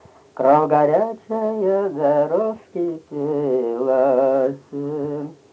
Произнесение частицы –ся в возвратных формах глаголов как –се